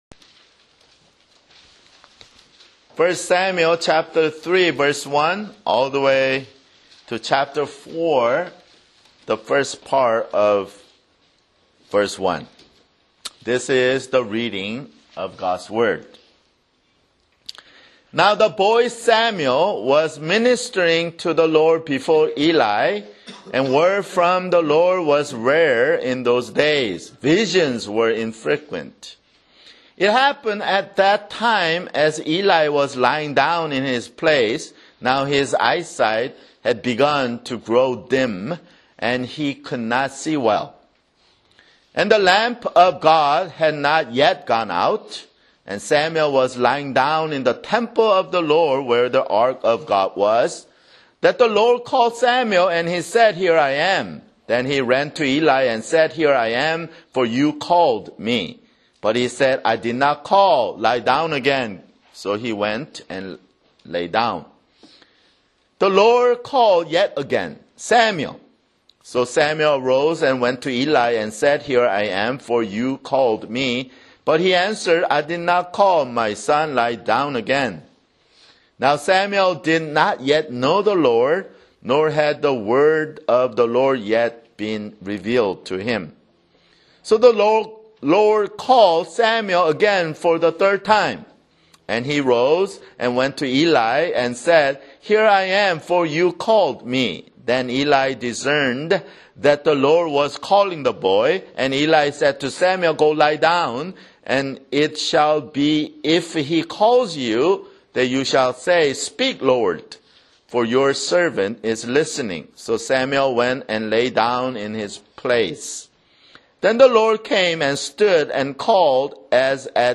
Download MP3 (Right click on the link and select "Save Link As") Labels: Sermon - 1 Samuel